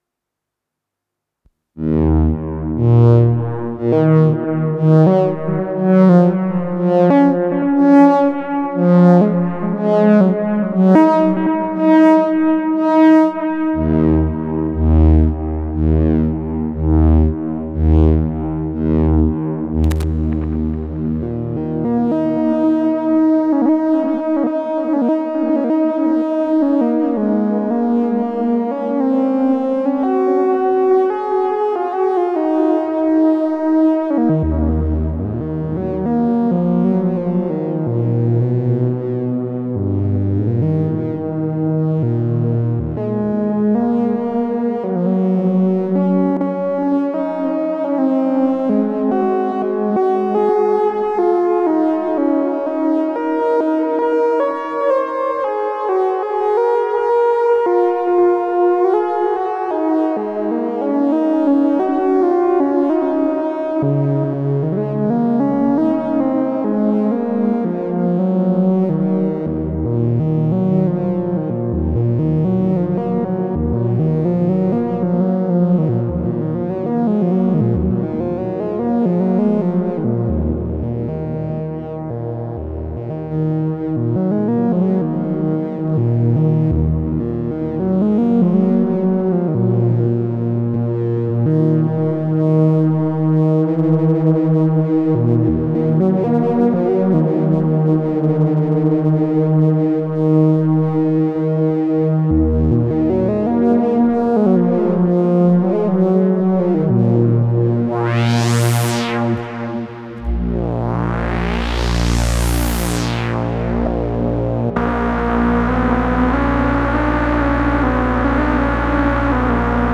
Аппарат довольно не стабильный, может он плохо прогрелся, в комнате прохладно, + 18°С, но зато он очень пробивной, забивает все, что звучит с ним одновременно.